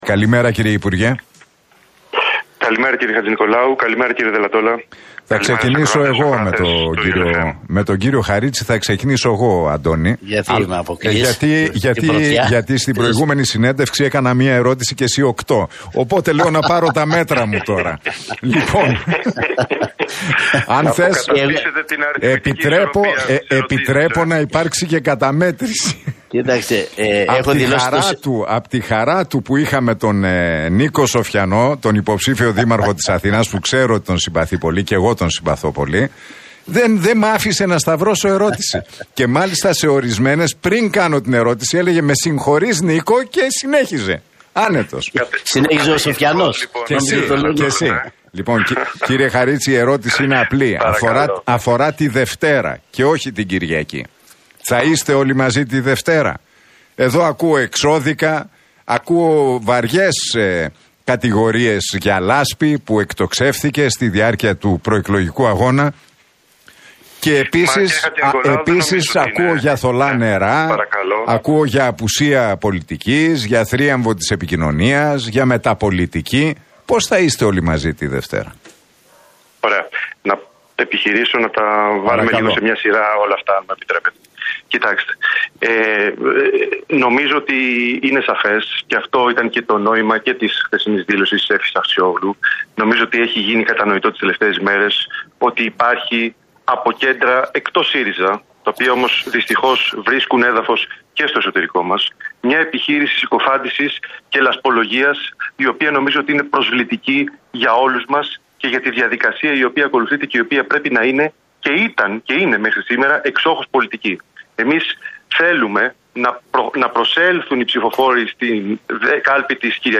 Χαρίτσης στον Realfm 97,8: Δεν προσήλθαμε χθες στη μάχη για τον ΣΥΡΙΖΑ - Η Έφη Αχτσιόγλου έχει συγκροτημένο σχέδιο